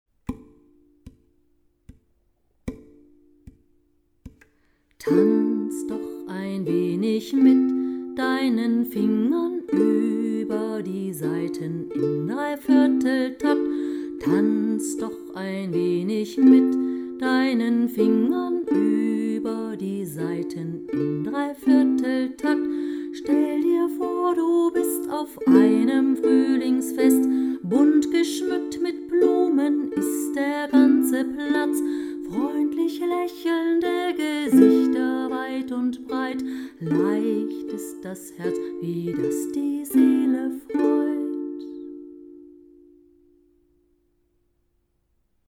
Langsame Fassung zum Üben: